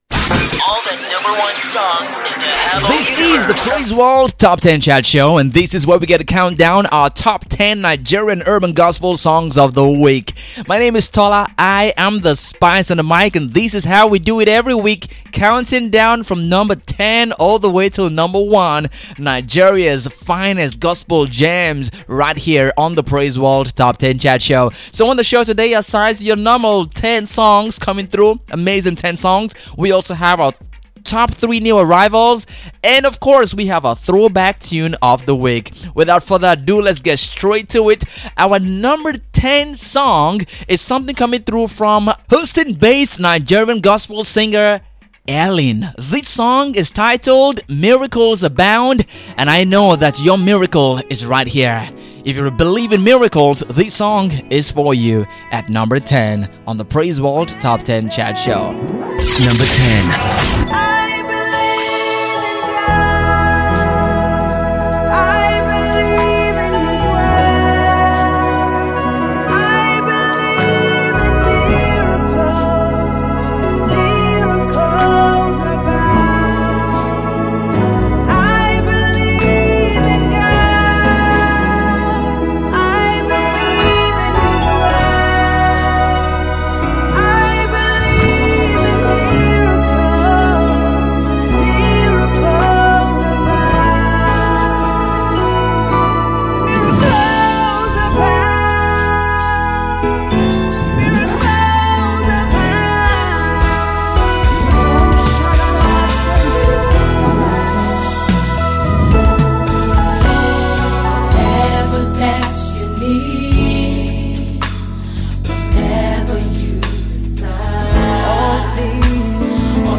Here is a countdown show of our top 10 Nigerian urban gospel songs of the week. 30 minutes of fun
Enjoy the sounds of Nigerian urban gospel music, and share with a friend.